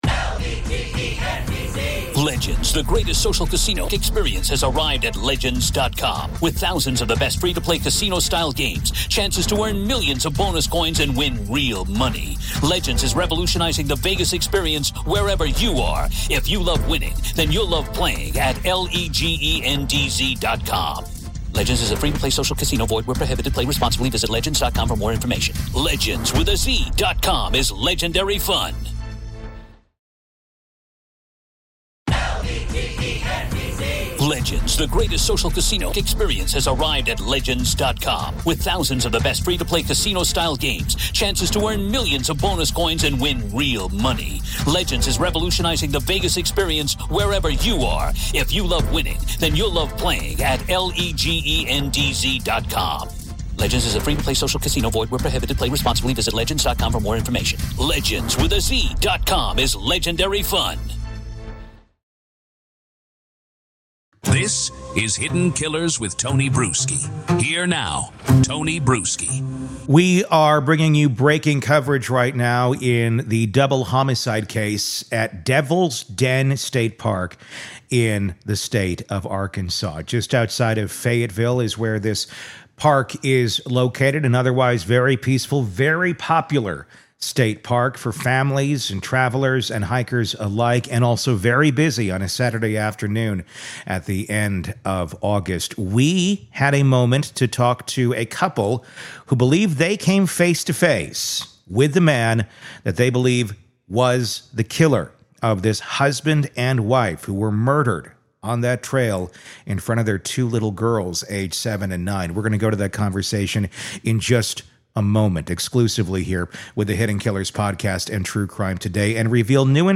EXCLUSIVE INTERVIEW With Couple Who Came Face to Face With Devil’s Den Double Homicide Suspect!